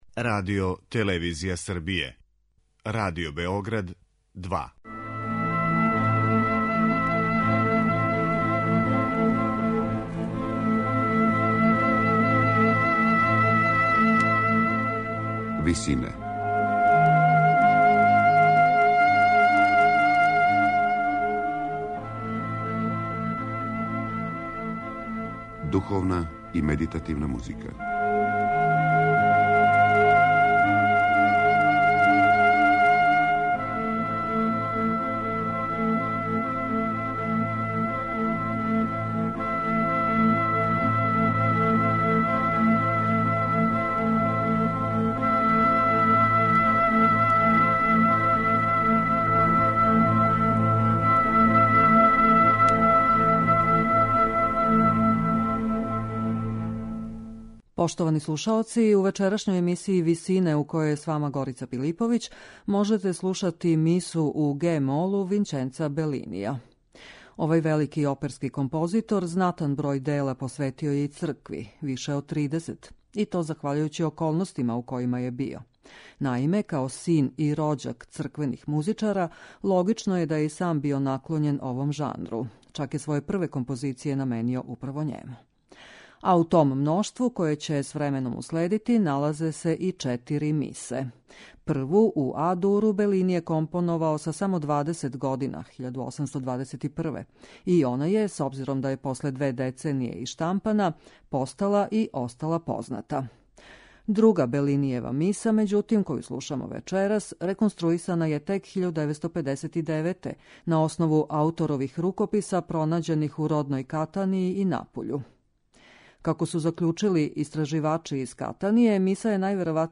Црквенa музикa аутора опера